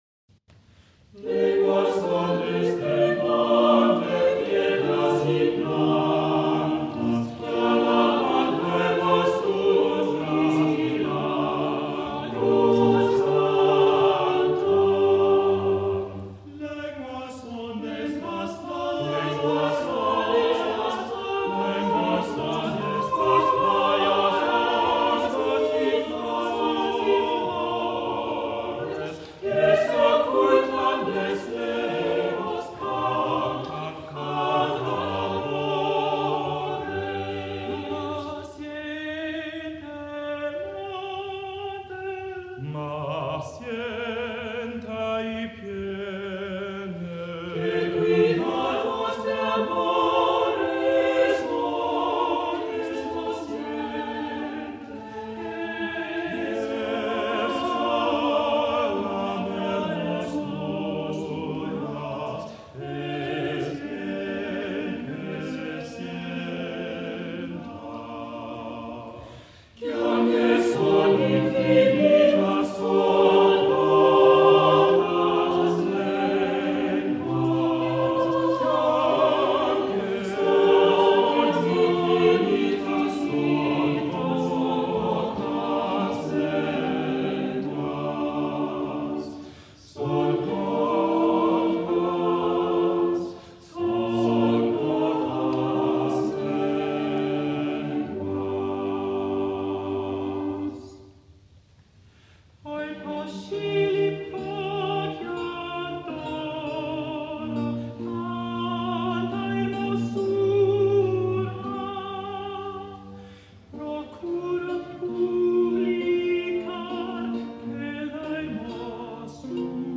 countertenor
bass